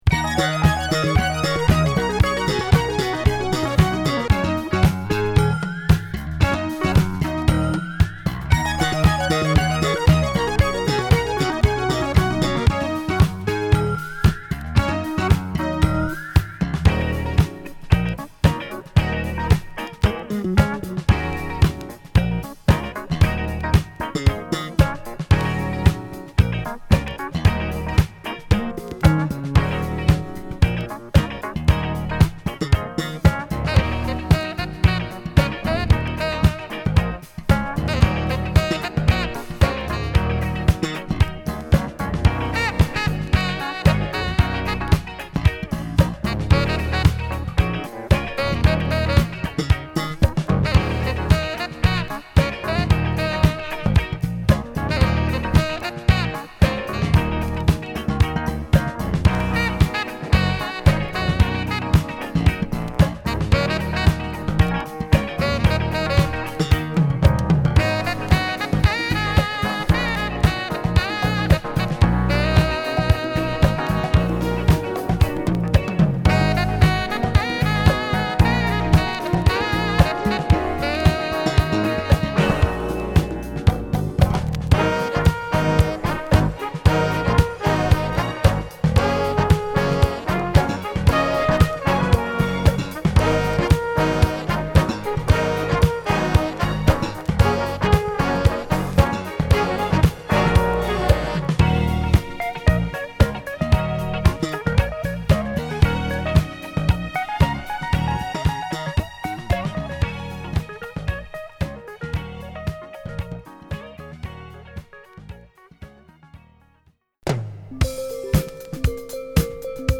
フュージョン〜ブルースな楽曲を収録！